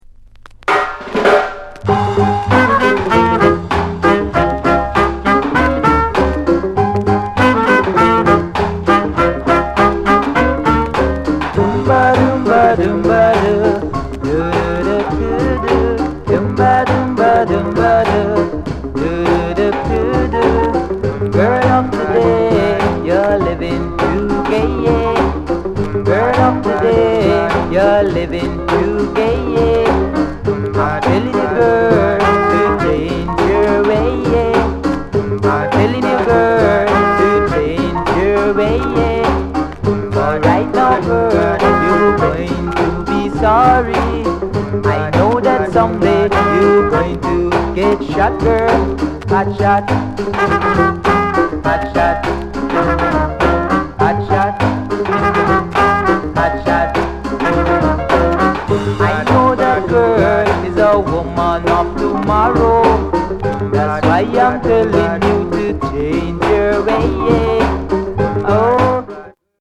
SOUND CONDITION A SIDE VG
ROCKSTEADY